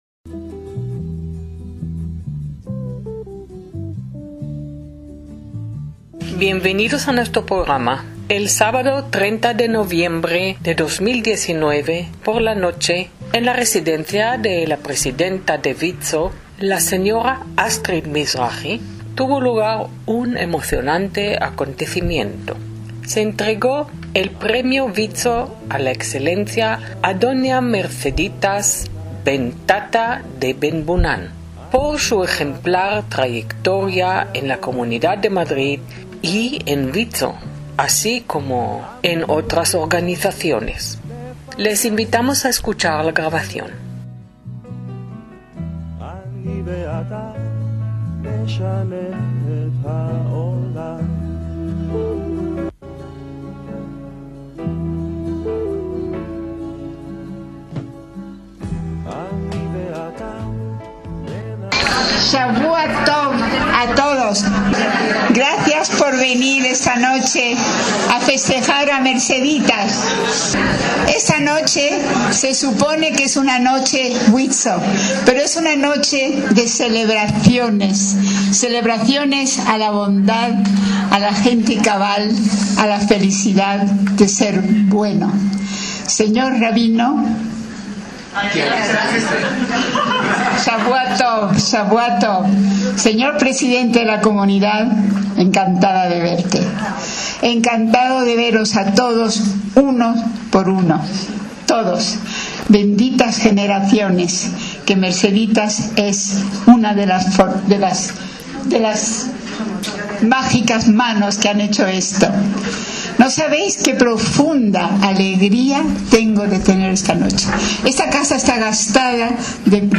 ACTOS EN DIRECTO